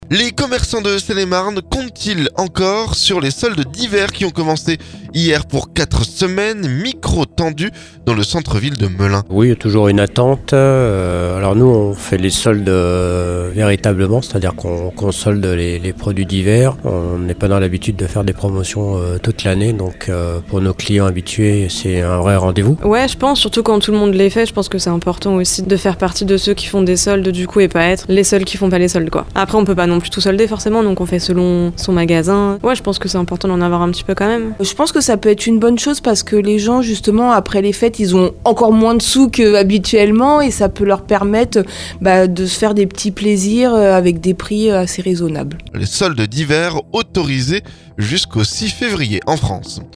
Micro tendu dans le centre-ville de Melun.